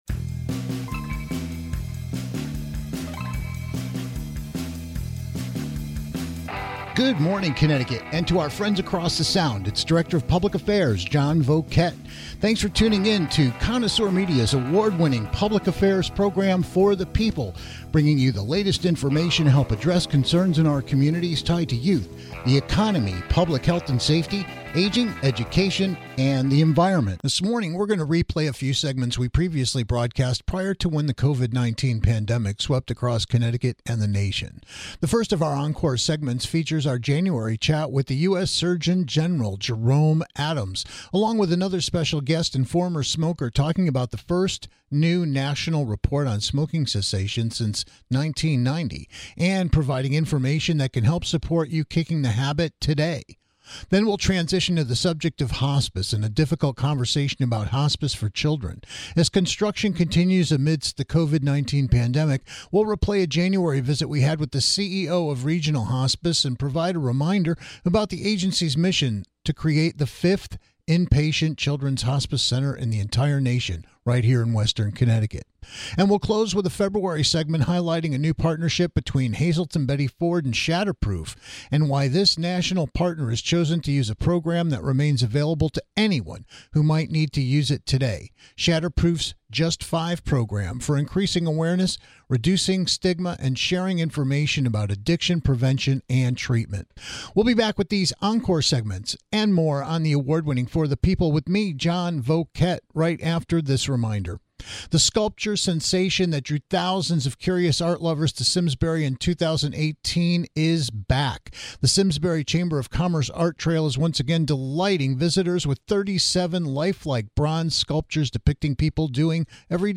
Our first encore segment features U.S. Surgeon General Jerome M. Adams talking about the first new national report on smoking cessation since 1990, and ways to begin kicking the habit today.